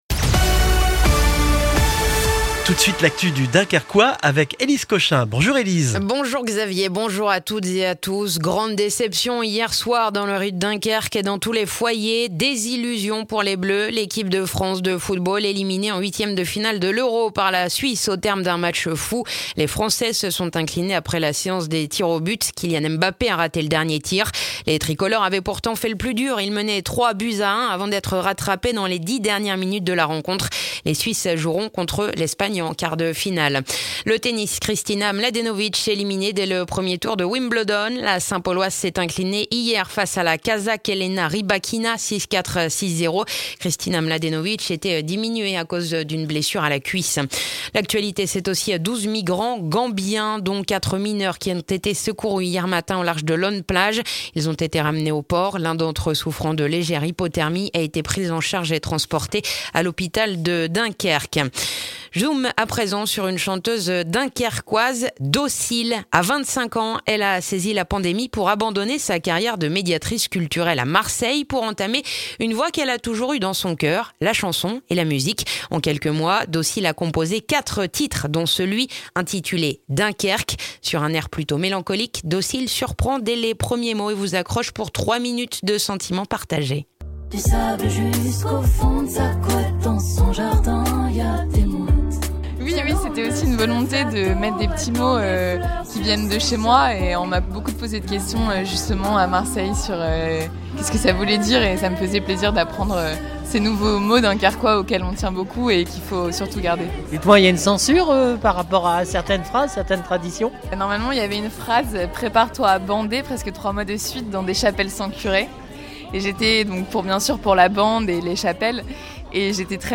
Le journal du mardi 29 juin dans le dunkerquois